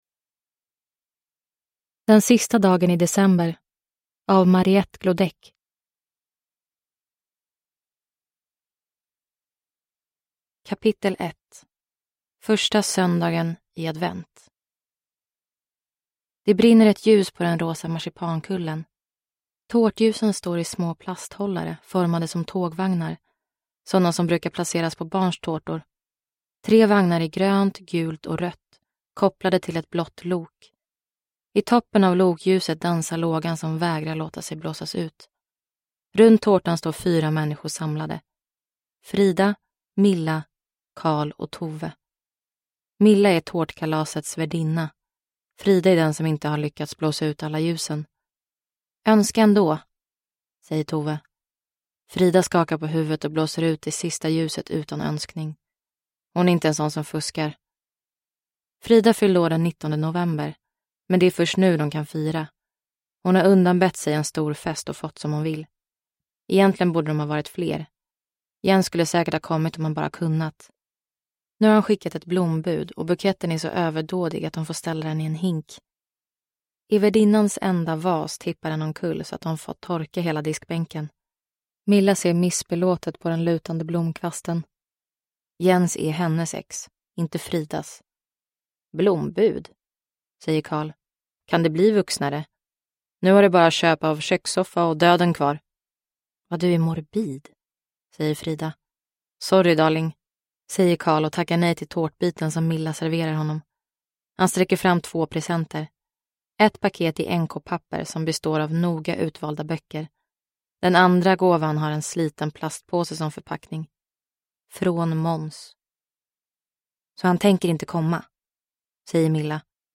Den sista dagen i december – Ljudbok – Laddas ner